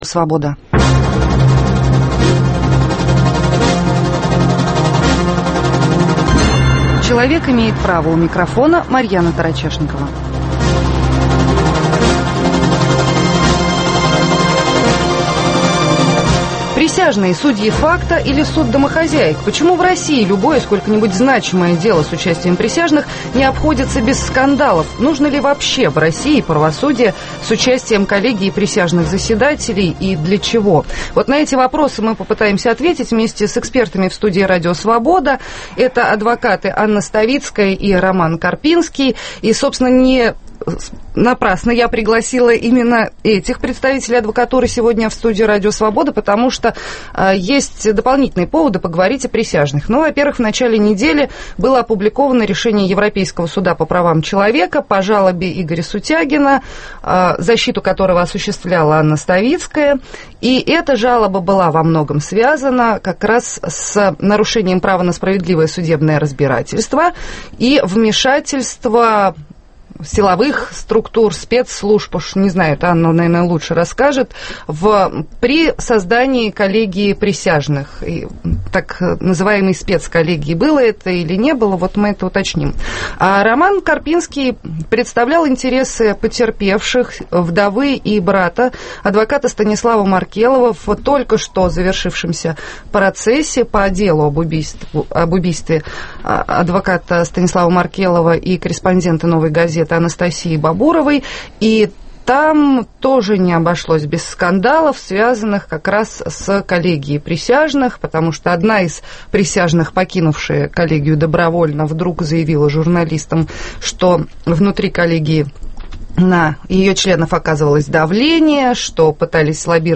Почему в России любое сколько-нибудь значимое дело с участием присяжных не обходится без скандалов? В студии РС